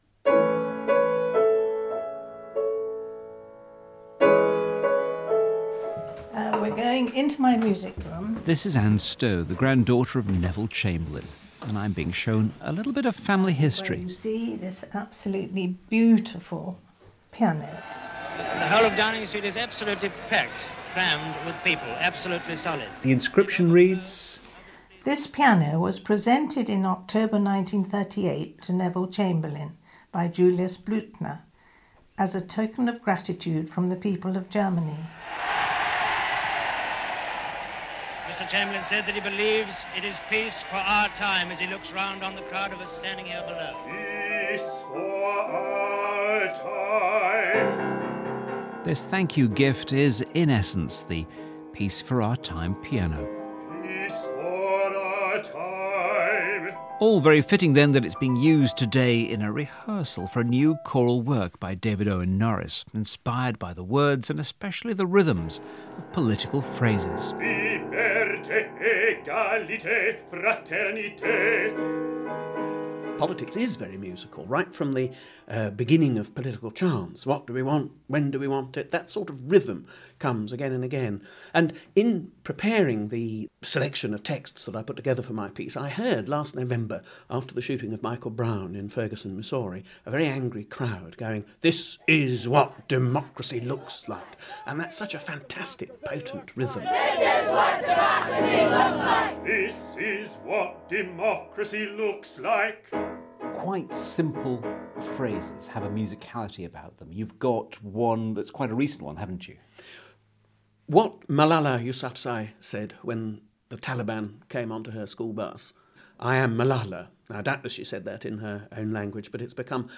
A new choral work